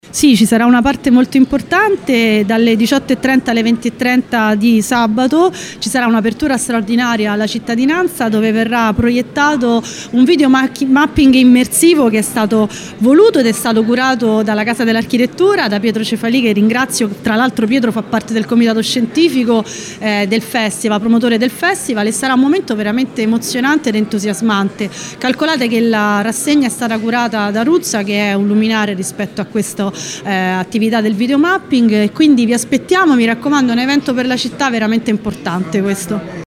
Proprio il Ruspi sarà uno dei luoghi in cui si svolgerà il Festival dell’Architettura (13-15 dicembre 2024) il primo a Latina , dedicato al Razionalismo. Un videomapping immersivo proposto dalla Casa dell’Architettura come ci racconta qui, l’assessore all’Urbanistica Annalisa Muzio.